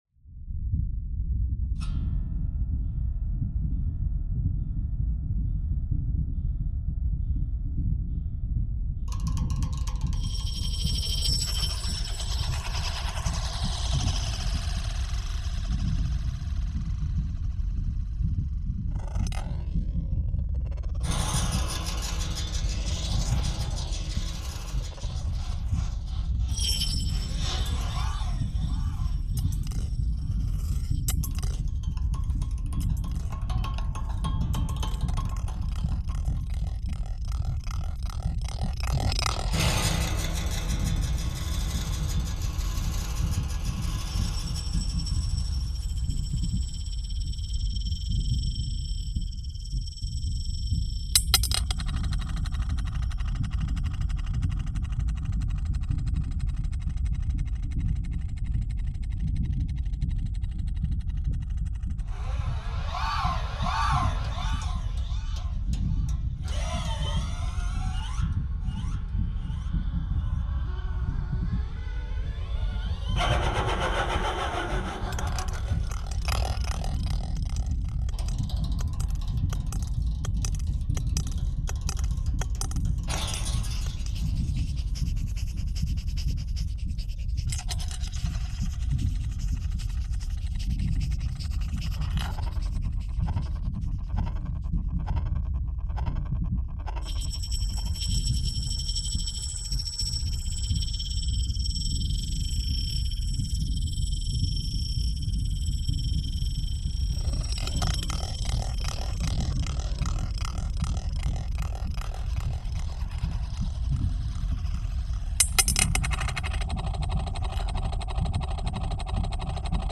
The rumble is a manipulated sample of leaves being crunched.